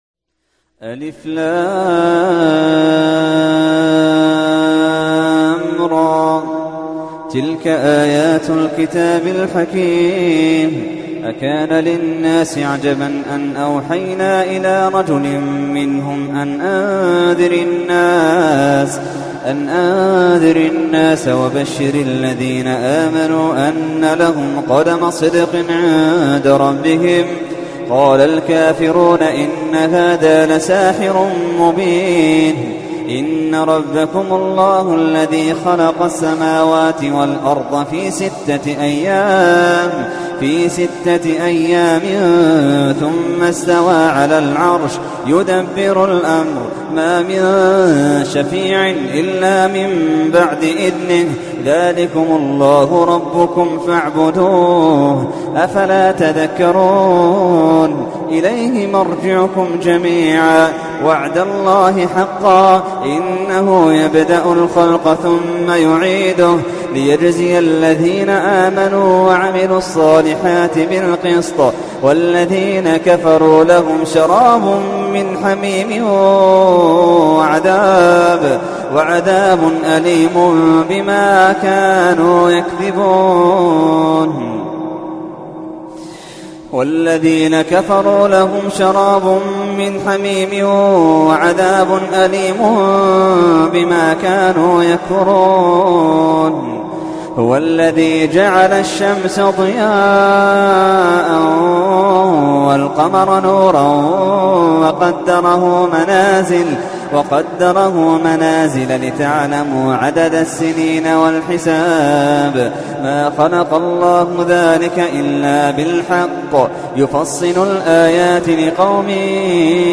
تحميل : 10. سورة يونس / القارئ محمد اللحيدان / القرآن الكريم / موقع يا حسين